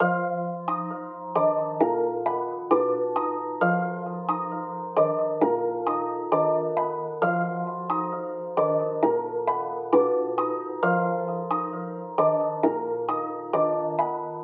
库巴兹型钟声
Tag: 133 bpm Trap Loops Bells Loops 2.43 MB wav Key : D FL Studio